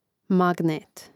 màgnēt magnet